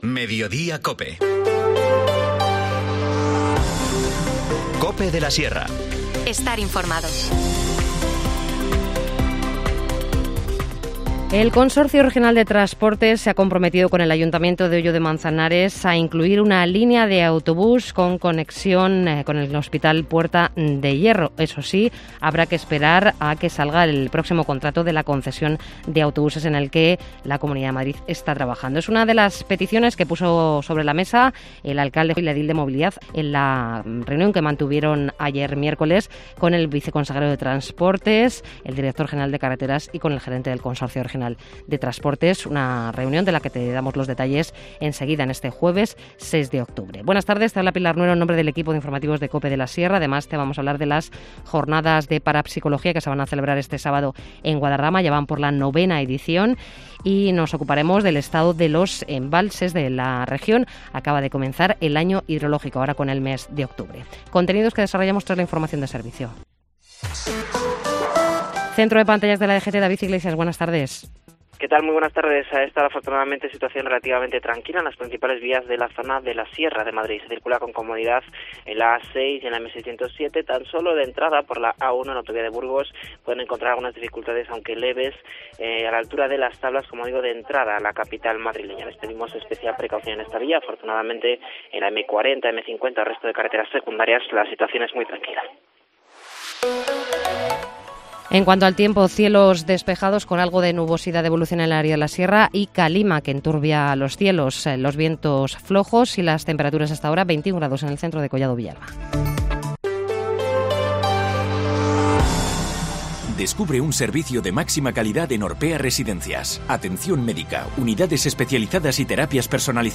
Informativo Mediodía 6 octubre